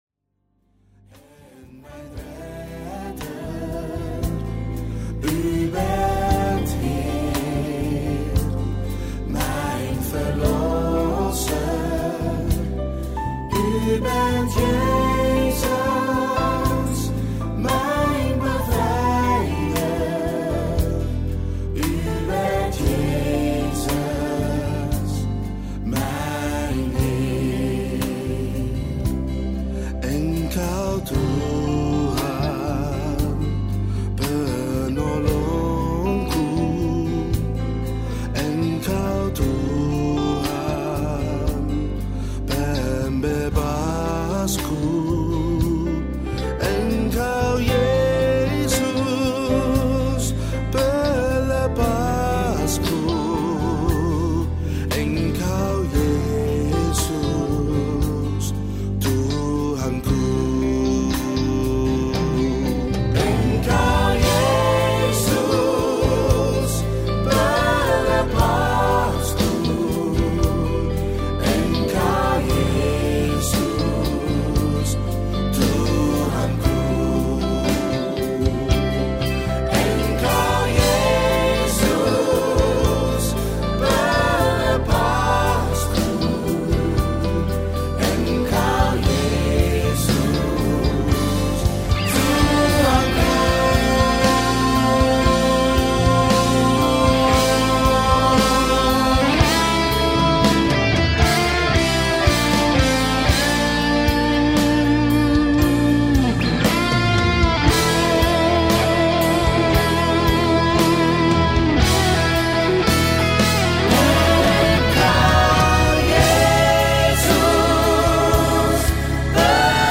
Gospel Pop, Praise & Worship